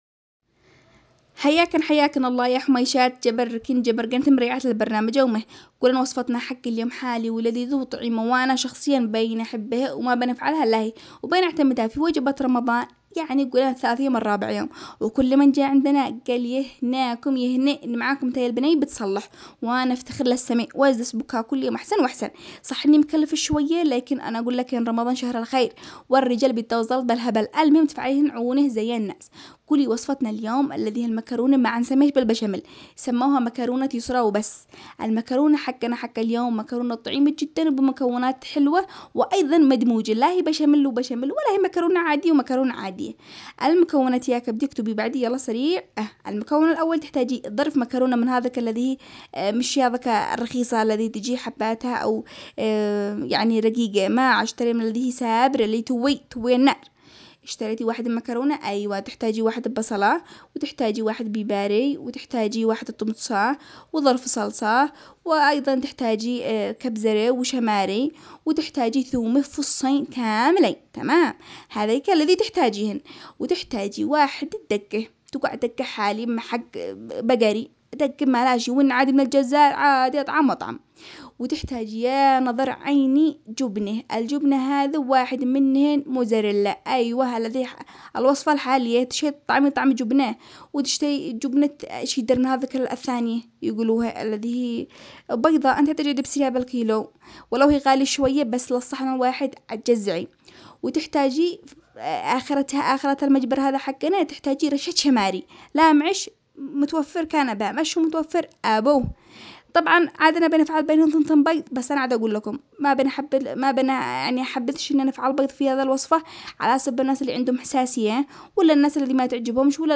جديد الاخبار والدراسات والاكتشافات العلمية التي تخص المرأة .. نشرة اخبارية تهتم بأخبار المرأة اليمنية والفعاليات والانشطة التي تشارك فيها وتخصها